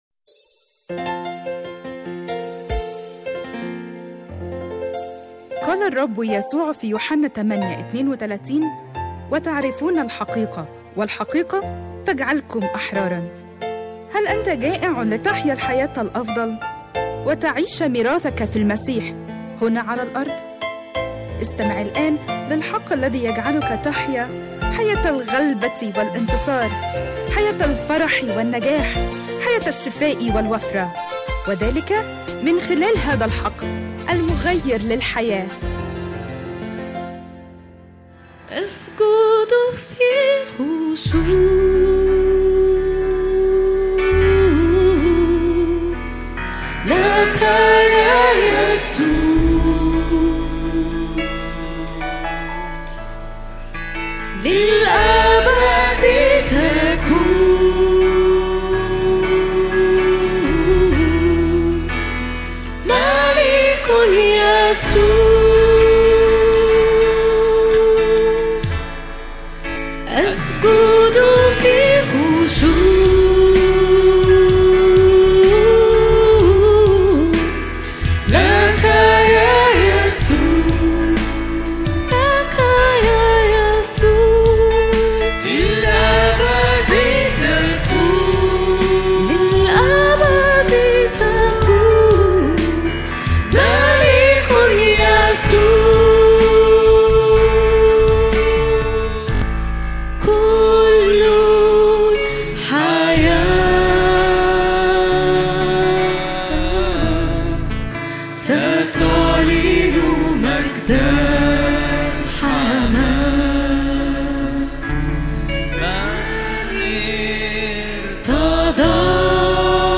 🎧 Download Audio 1 تحميل اجتماع الثلاثاء 3/3/2026 لسماع العظة على الساوند كلاود أضغط هنا لمشاهدة العظة على اليوتيوب من تأليف وإعداد وجمع خدمة الحق المغير للحياة وجميع الحقوق محفوظة.